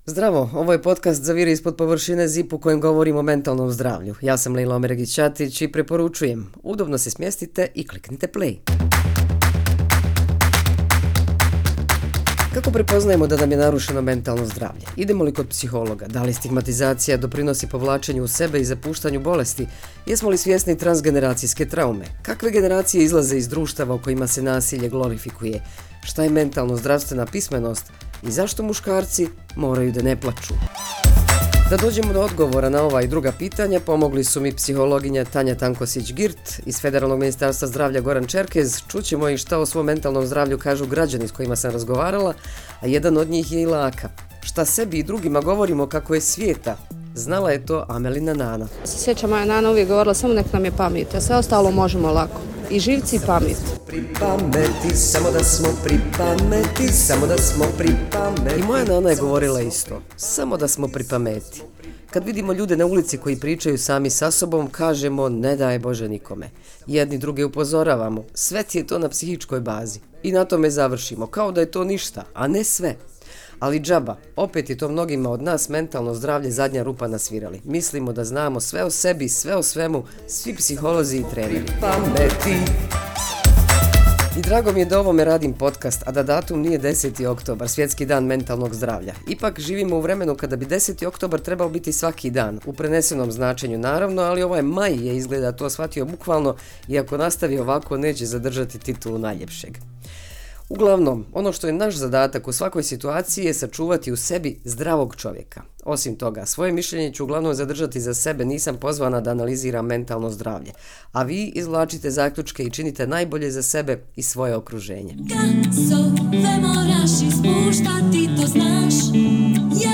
ali i građani na ulicama Sarajeva među kojima je bio i muzičar Laka.